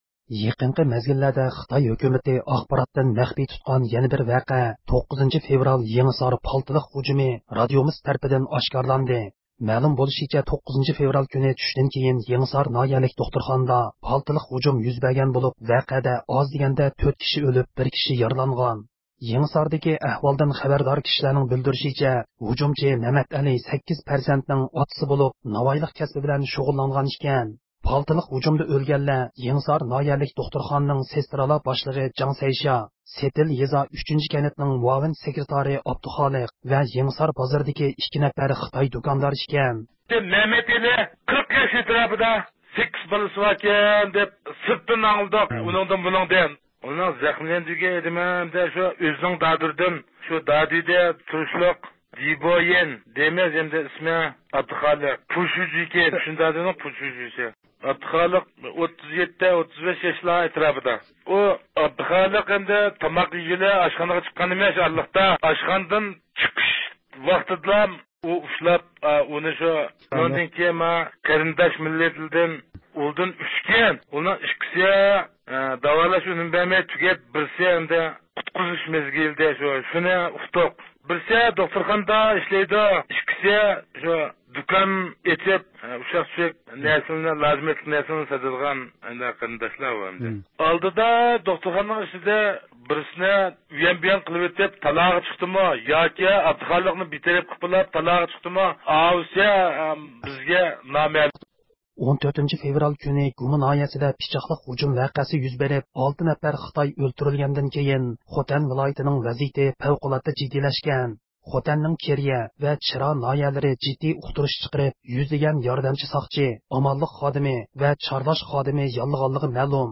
ھەپتىلىك خەۋەرلەر (25-فېۋرالدىن 3-مارتقىچە) – ئۇيغۇر مىللى ھەركىتى